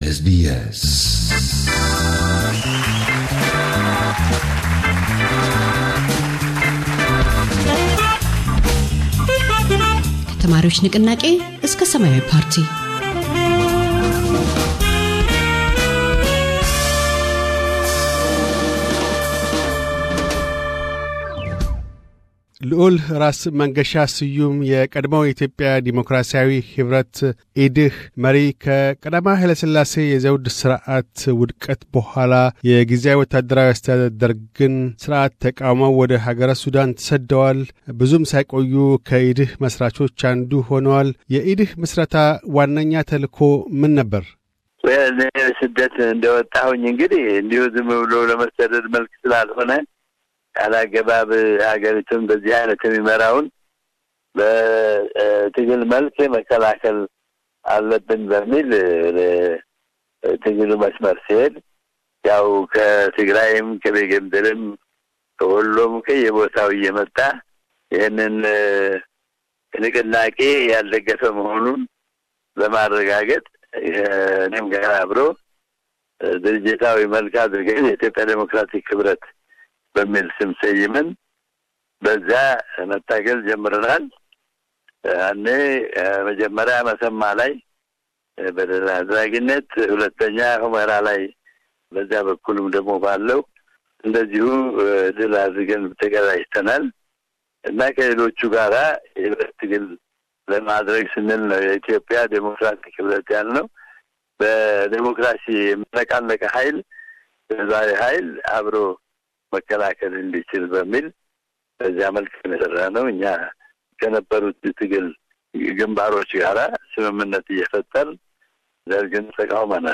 ልዑል ራስ መንገሻ ስዩም፤ ስለ የኢትዮጵያ ዲሞክራሲያዊ ኅብረት/ፓርቲ የትግል እንቅስቃሴ ታሪክና አስተዋጽኦዎችን ይናገራሉ።